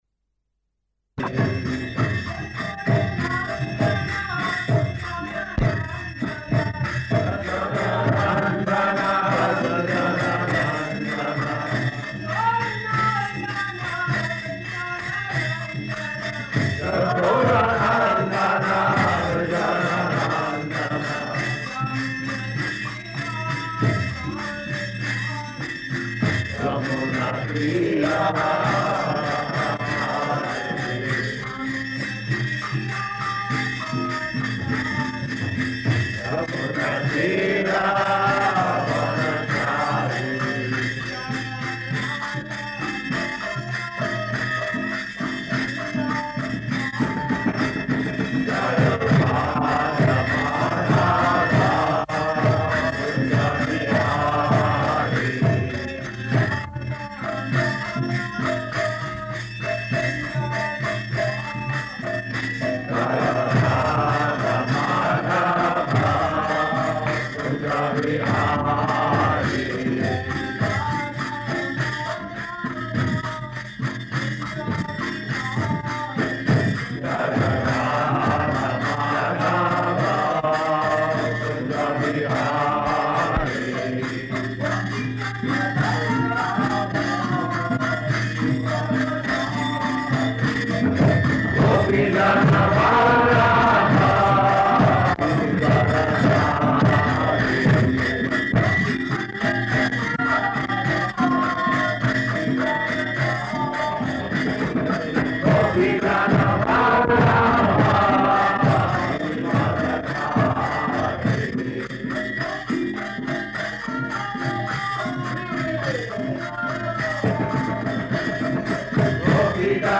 Type: Srimad-Bhagavatam
Location: Gorakphur
[ kīrtana ]